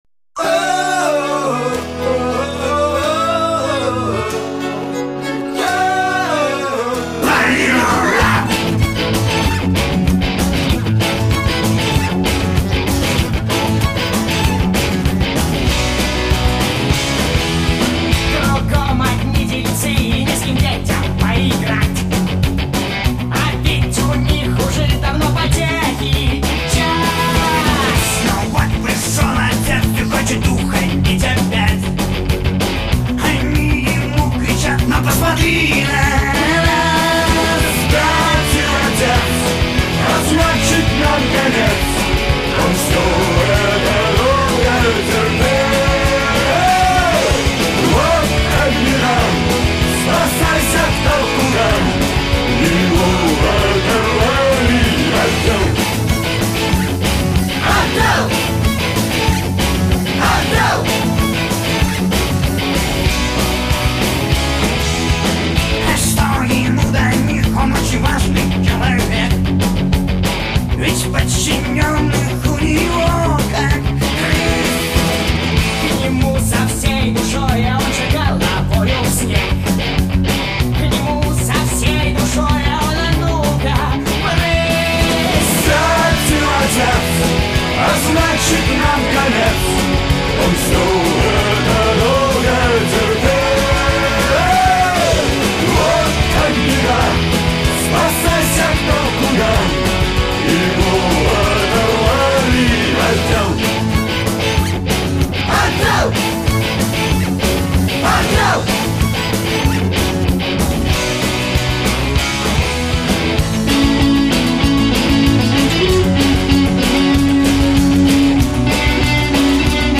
Рок [115]